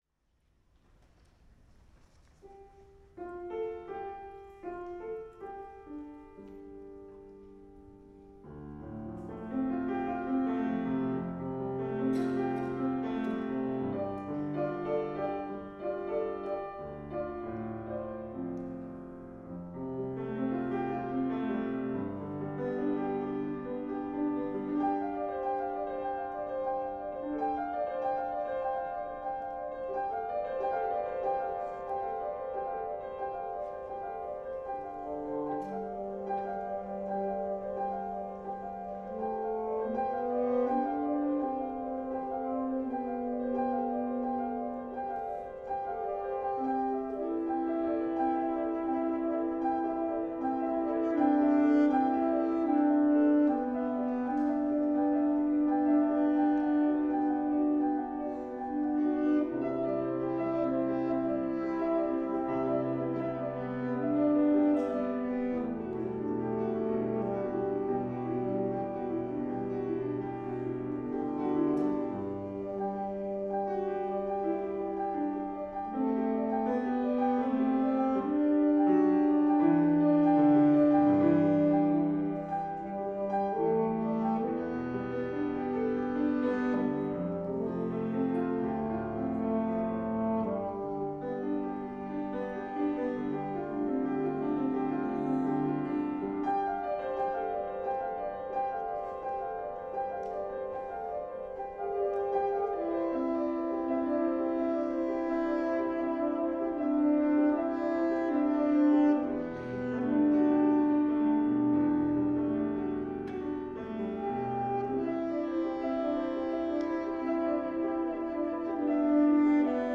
for Alto Saxophone and Piano (2017)